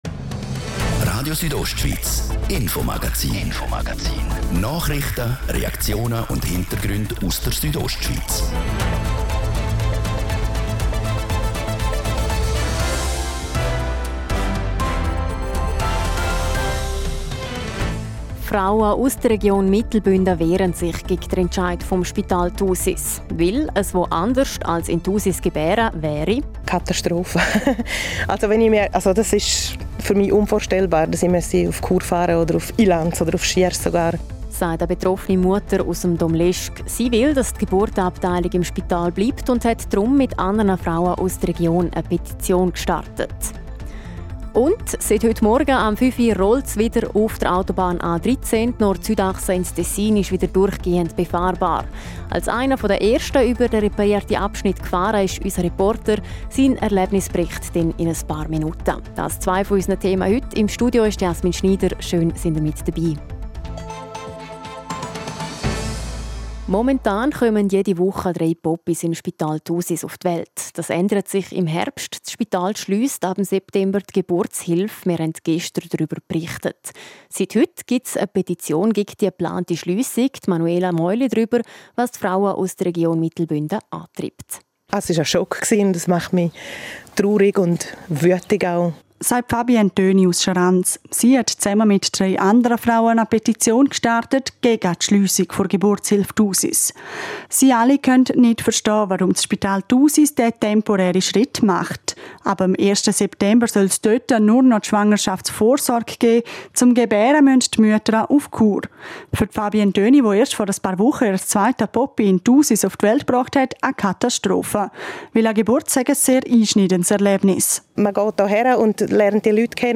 Ein Erlebnisbericht.